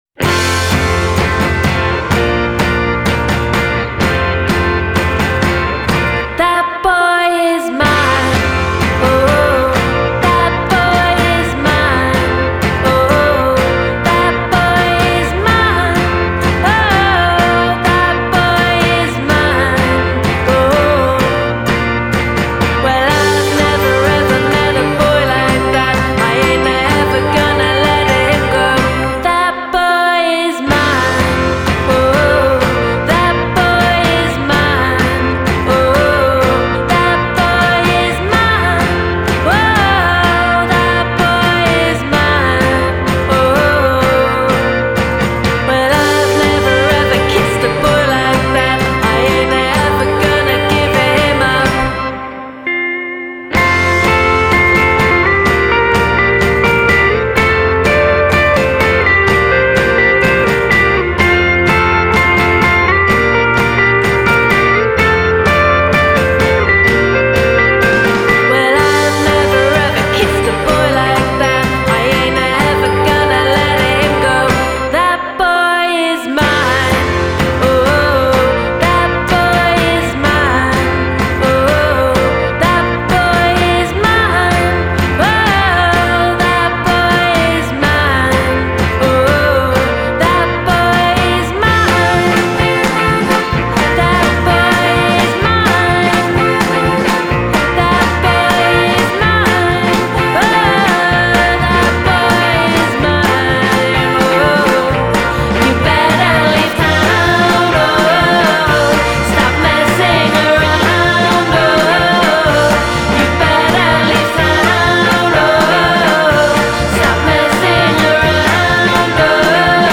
Genre: Indie Pop / Twee